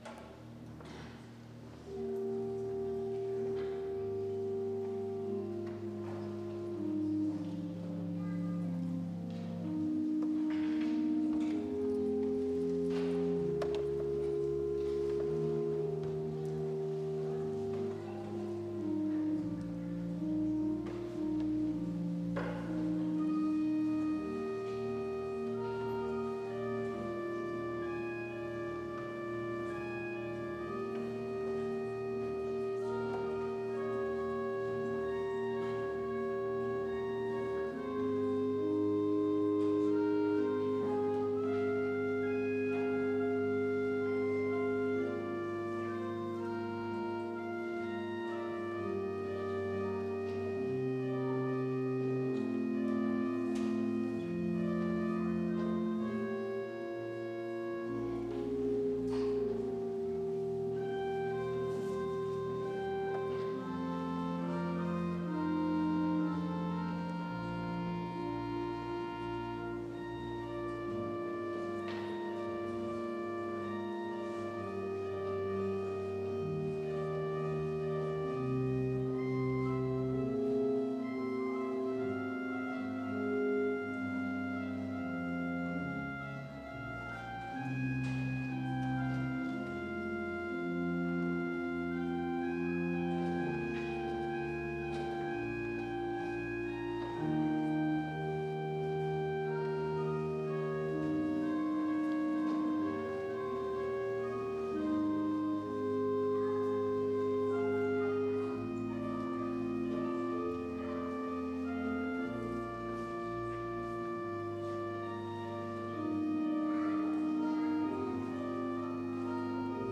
Orgelnachspiel zum Ausgang
Audiomitschnitt unseres Gottesdienstes am Sonntag Okuli 2023.